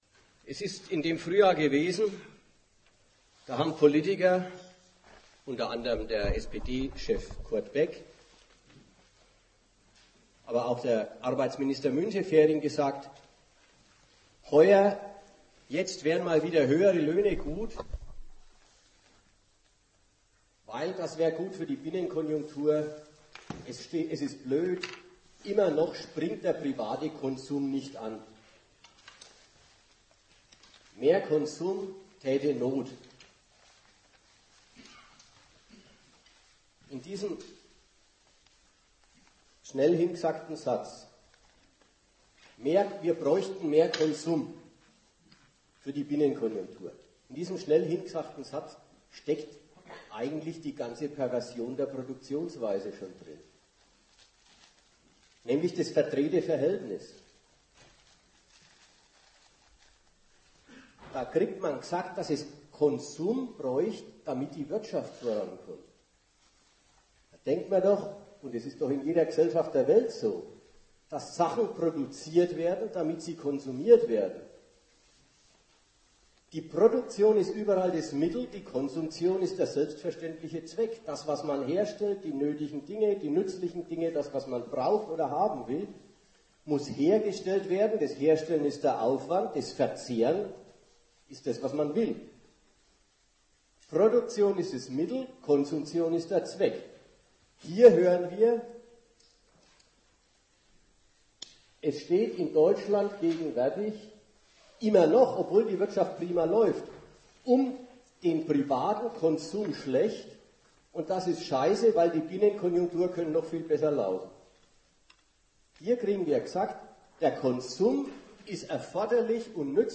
Eine Kritik der Konsumkritik, sowie Aufklärung über die perverse Rolle, die der Konsum in einer Wirtschaft tatsächlich spielt, in der es um das Wachstum des Kapitals geht, wird in dem Vortrag geleistet.